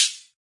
hit hats freesound » hats (17)
标签： hat drums cymbal hh percussion high hats hihat hit oneshot
声道立体声